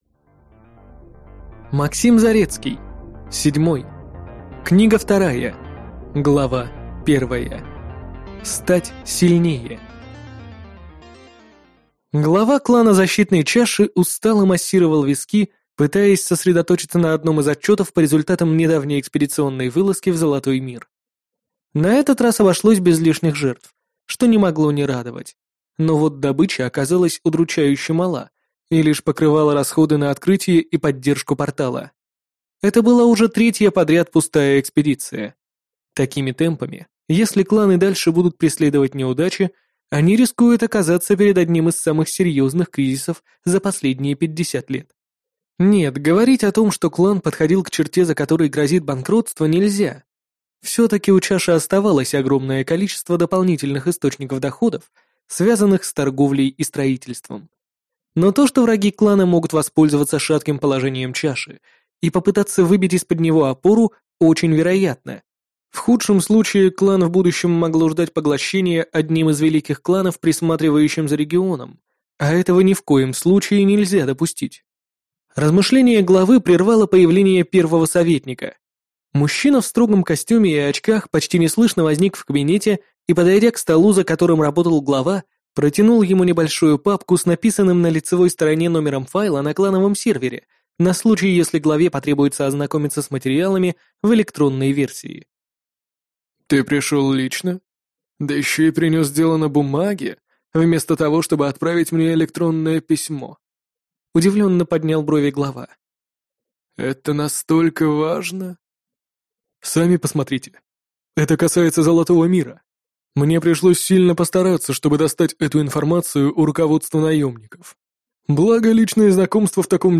Аудиокнига Седьмой. Книга 2 | Библиотека аудиокниг